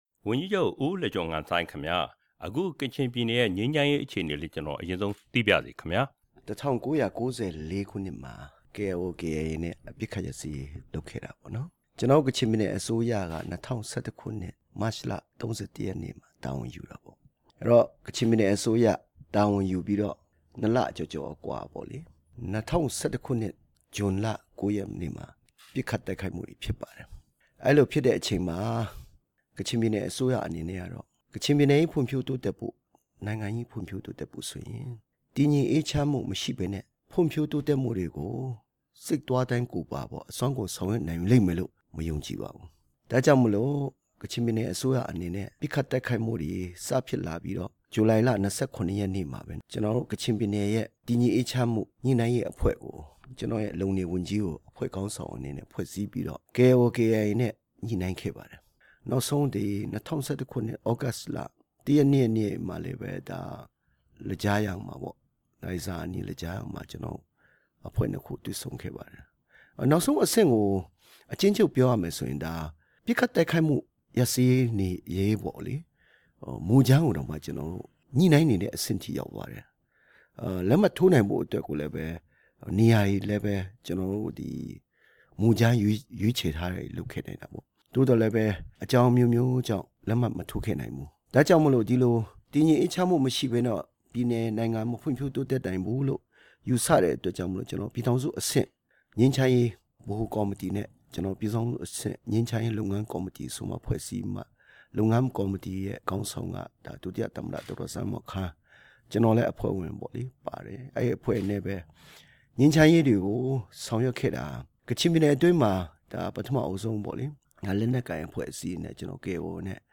ကချင်ပြည်နယ်ဝန်ကြီးချုပ်နဲ့ မေးမြန်းချက်